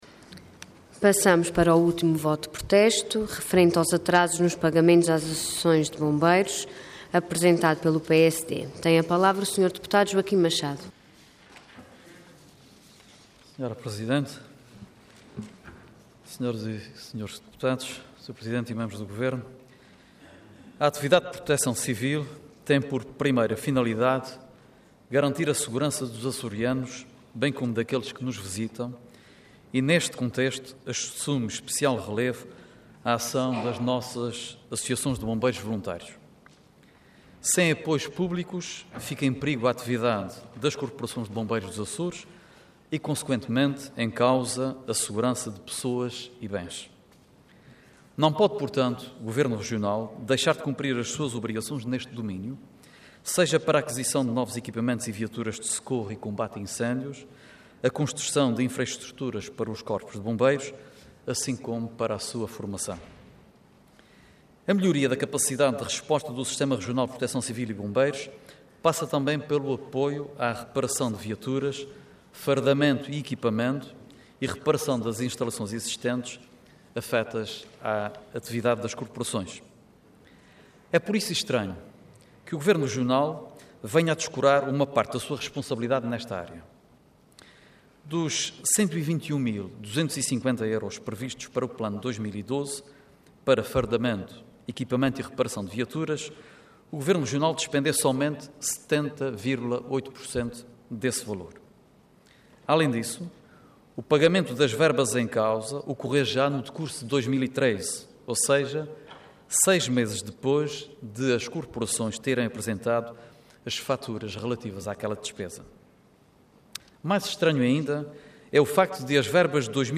Intervenção
Orador Joaquim Machado Cargo Deputado Entidade PSD